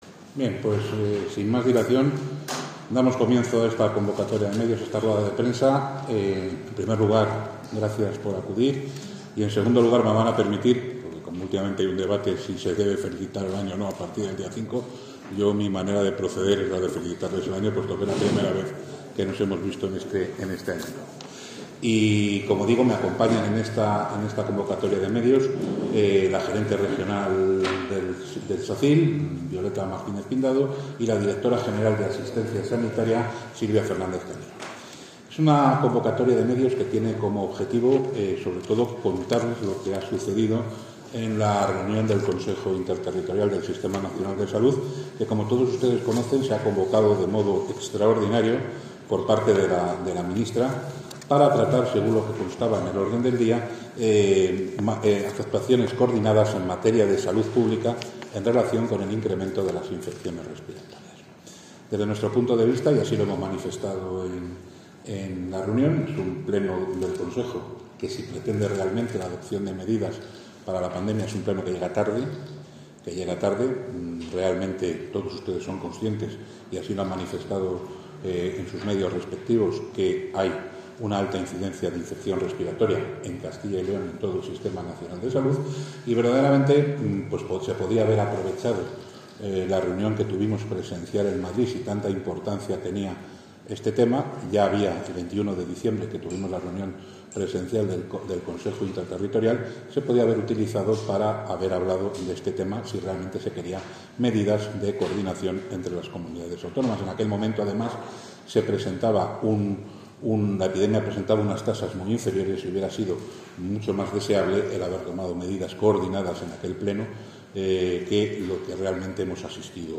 Valoraciones del consejero.
El consejero de Sanidad, Alejandro Vázquez, presenta los datos sobre la actividad en los hospitales de Castilla y León durante la época invernal y ofrece una valoración sobre el primer pleno del Consejo Interterritorial del Sistema Nacional de Salud.